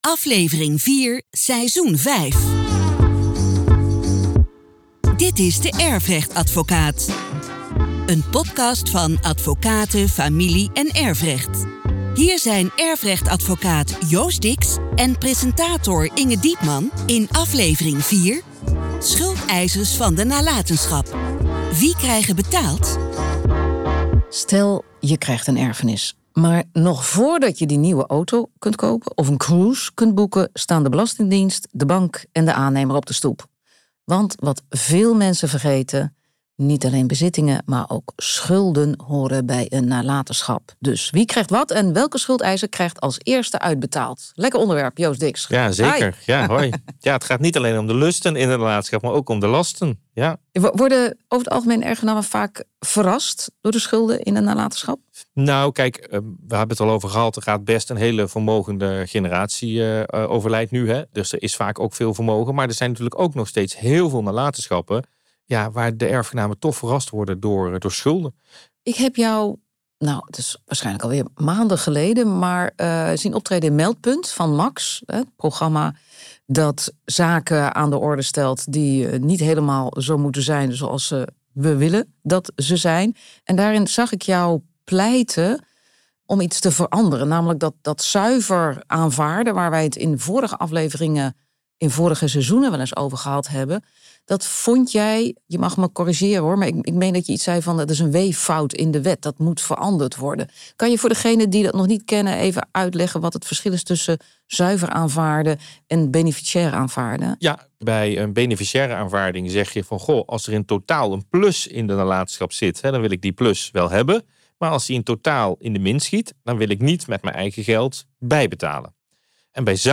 In deze podcastserie ‘De Erfrechtadvocaat’ van Advocaten Familie- & Erfrecht worden de meest gestelde vragen rondom de erfenis behandeld. Presentatrice Inge Diepman gaat in gesprek